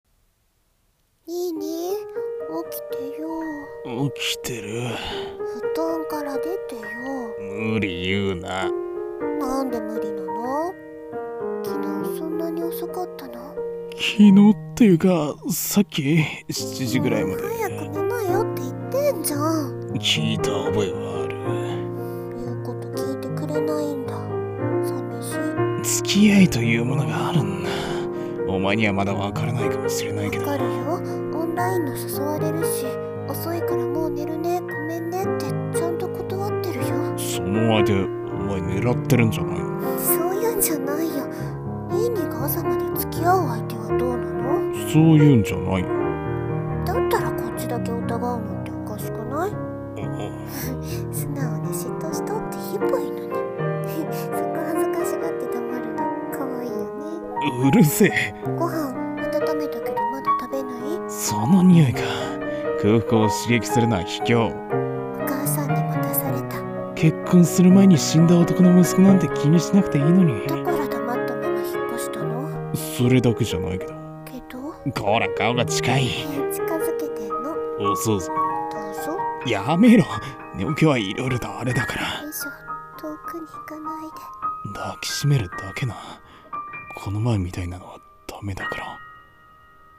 この前のは、秘密。【二人声劇】